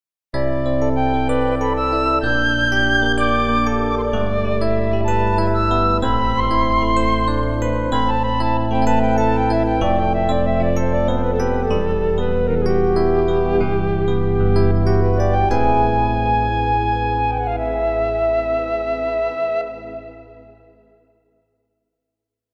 this duet - this solo - this duet (all Zebra2.2, no external effects)
Zebra2_realistic_flute_and_DX7.mp3